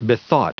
Prononciation du mot bethought en anglais (fichier audio)
Prononciation du mot : bethought